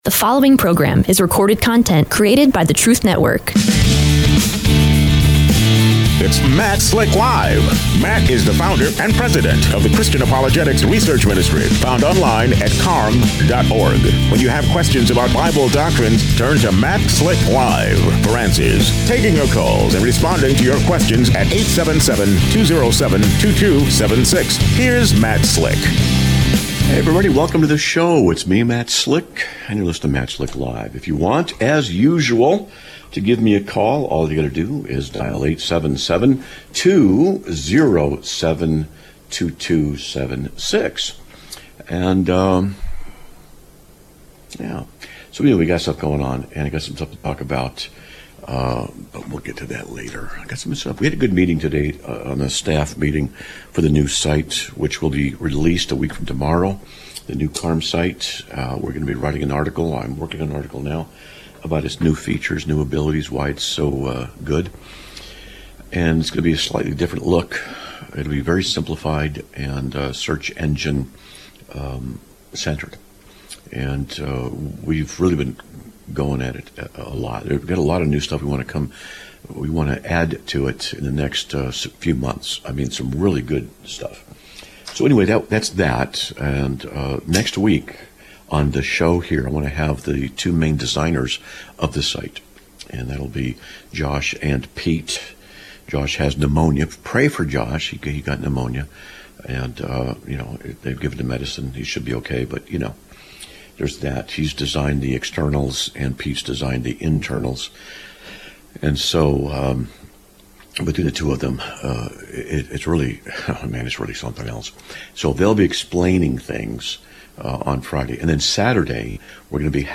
Live Broadcast of 10/17/2025